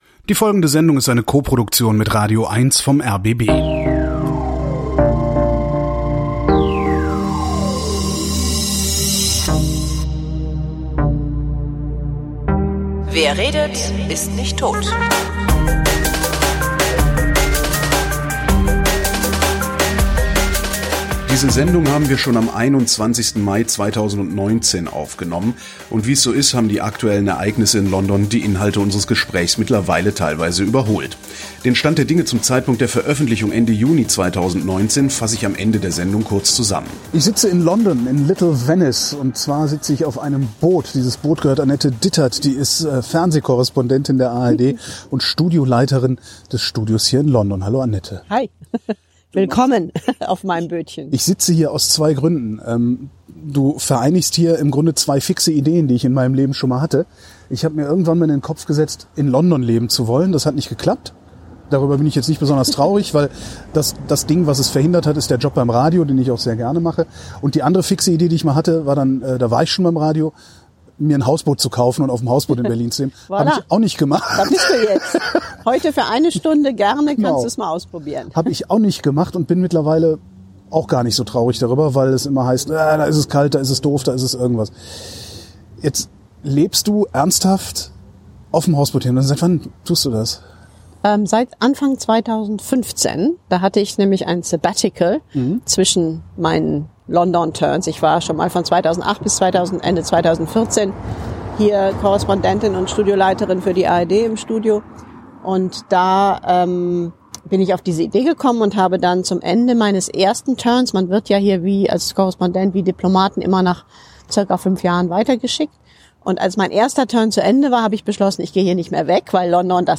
Im Mai war ich in London und hatte Gelegenheit, Annette in Little Venice zu besuchen und über Hausboote und – natürlich – den Brexit zu reden.